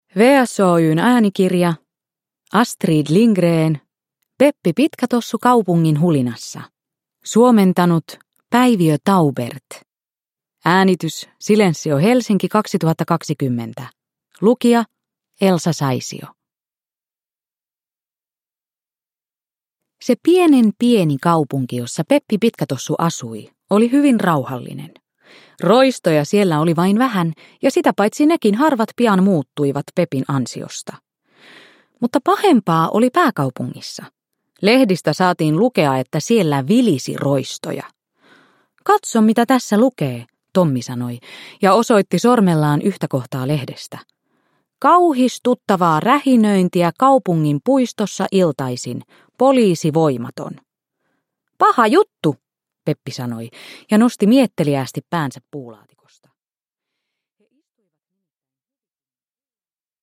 Peppi Pitkätossu kaupungin hulinassa – Ljudbok – Laddas ner